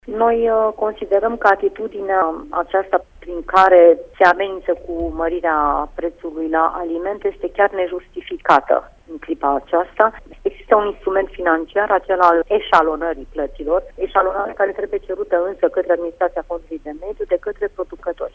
Pentru producătorii care nu pot plăti taxa pentru ambalajele nereciclate a produselor, există posibilitatea eșalonării, a mai spus Corina Lupu: